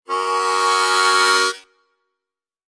Descarga de Sonidos mp3 Gratis: armonica 14.